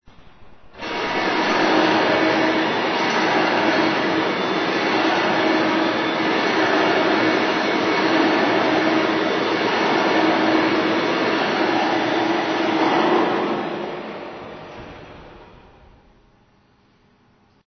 vacuum.mp3